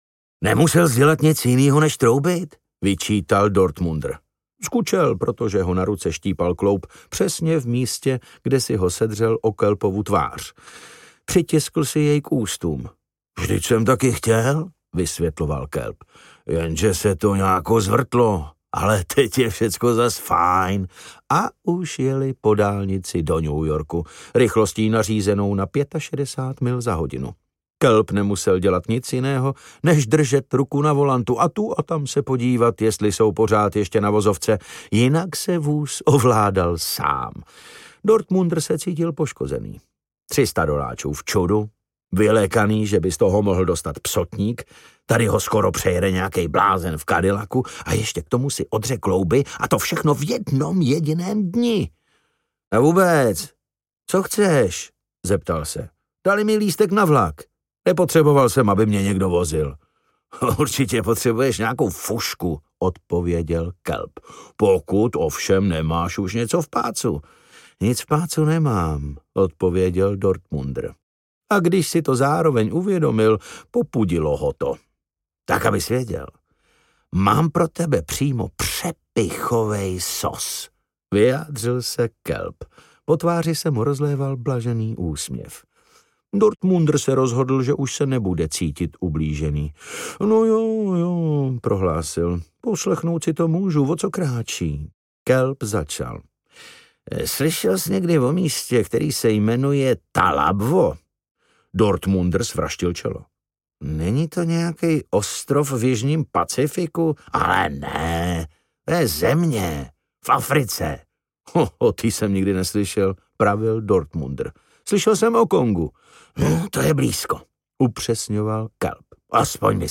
Ukázka z knihy
Čte David Novotný.
Vyrobilo studio Soundguru.
• InterpretDavid Novotný